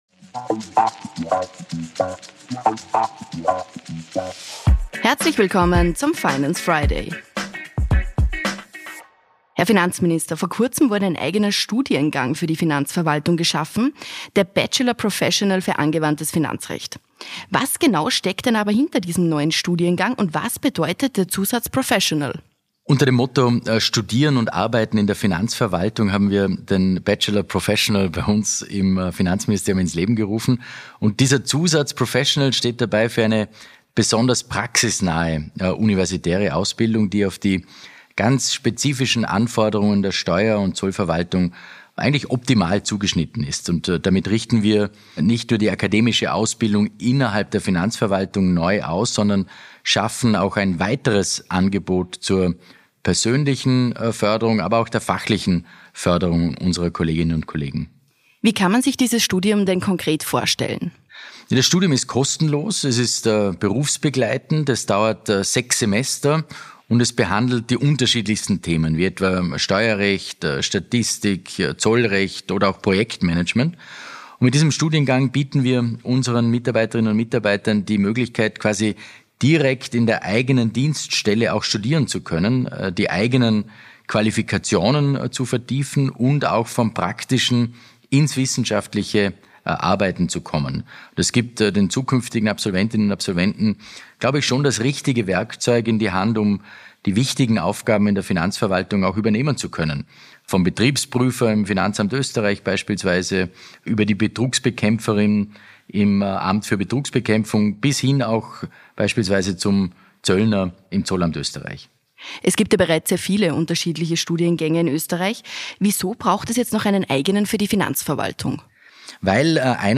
In der aktuellen Folge des „Finance Friday” spricht Finanzminister